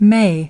27. may /meɪ/ : có thể